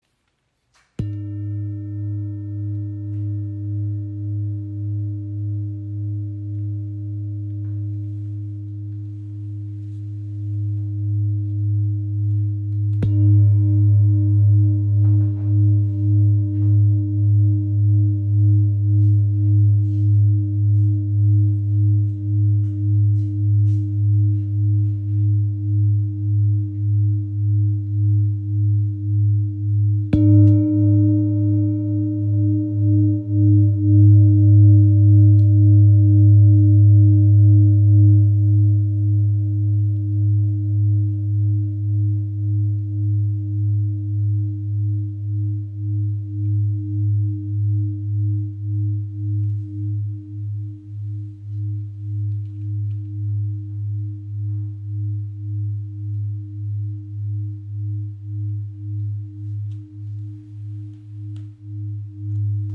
Klangschale mit Blume des Lebens | ø 30 cm | Ton ~ Ab | Uranus (104 Hz)
Handgefertigte Klangschale aus Kathmandu
• Icon Zentrierender Klang im Ton Ab | Planetenton Uranus (104 Hz) | 2577 g.
Ihr obertonreicher Klang im Ton Ab ist klar und zentrierend.